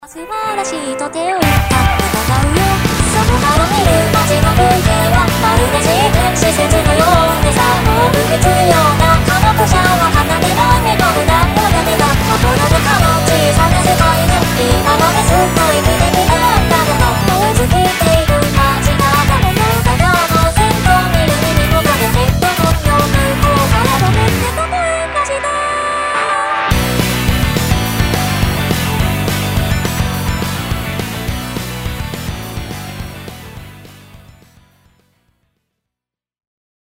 ロック・ポップス・トランス・ヒーリング等の様々な音楽に載せて一足先に